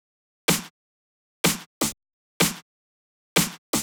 32 Snare.wav